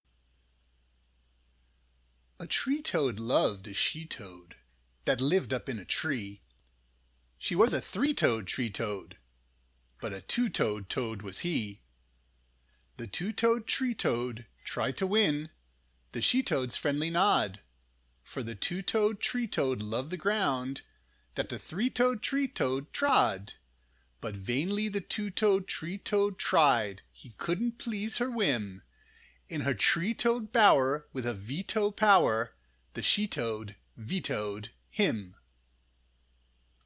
This poem works best when you read it slowly.
It can also be read like a tongue twister.